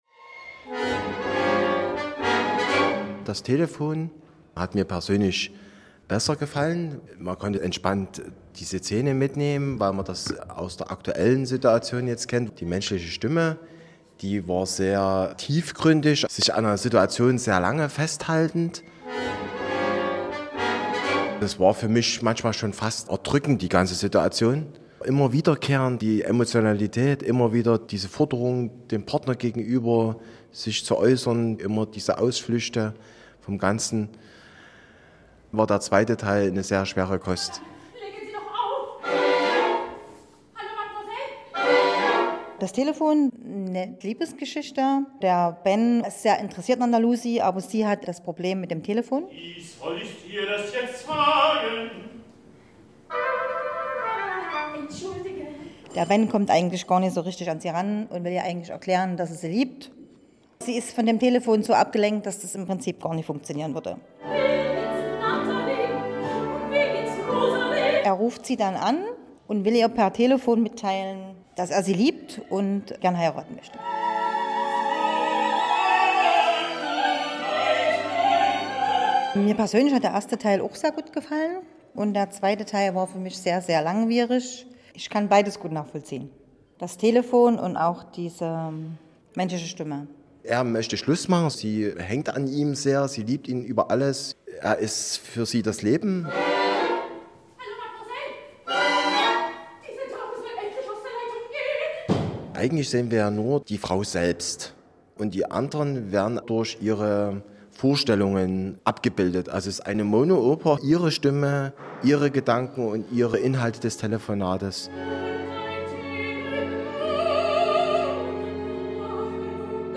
Zuschauer-Umfrage des MDR bei der Aufführung in Döbeln (mp3, 3.8MB)